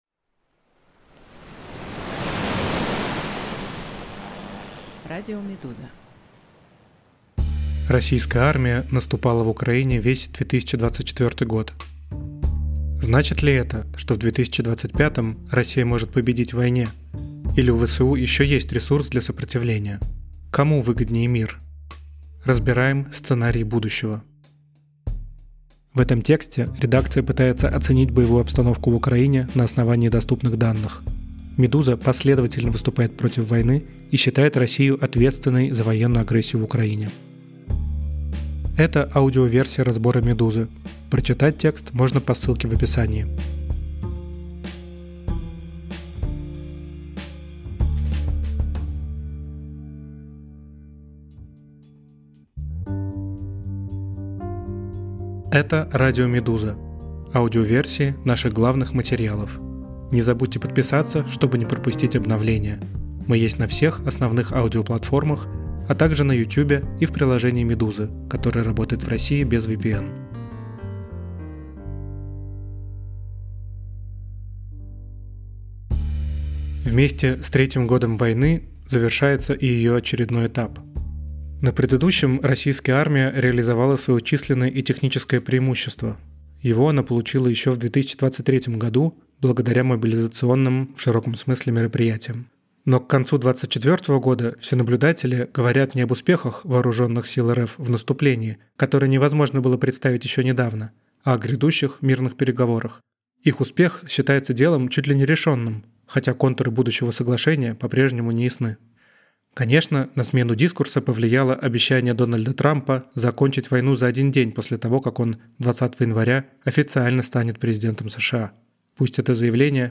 Аудиоверсии главных текстов «Медузы». Расследования, репортажи, разборы и другие материалы — теперь и в звуке.